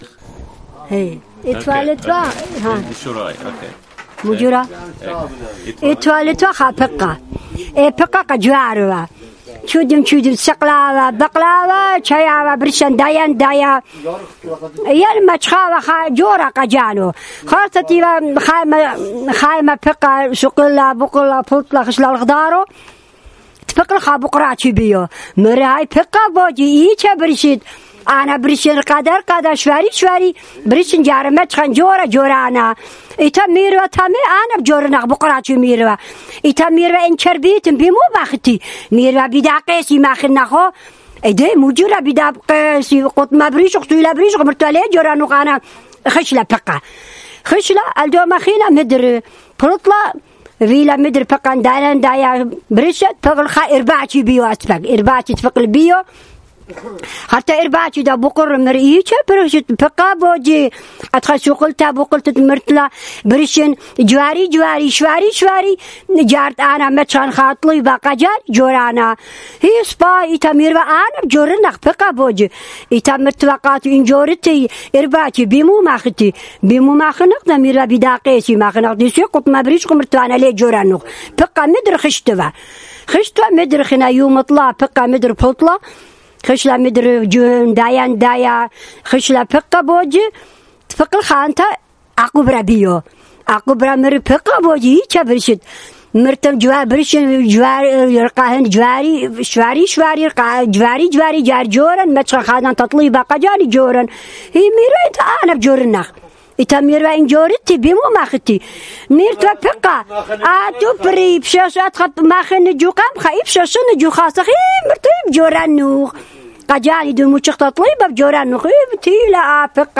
Urmi, Christian: A Frog Wants a Husband